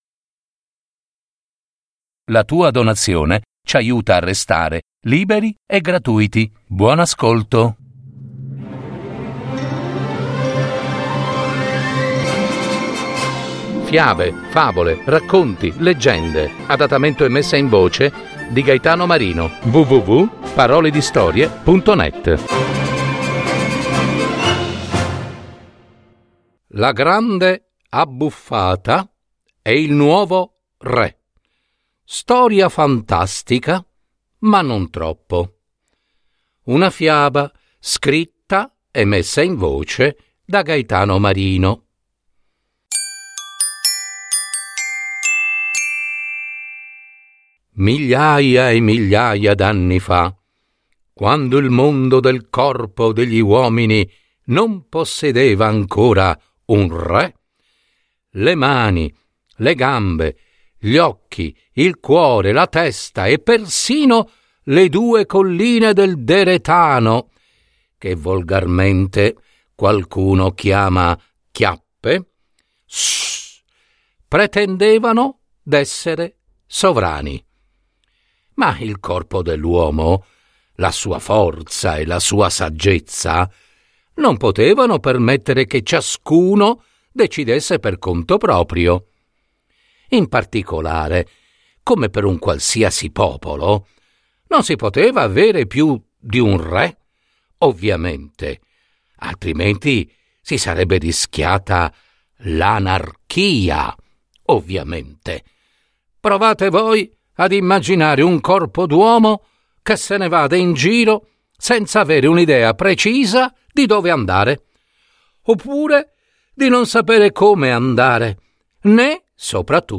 Fiaba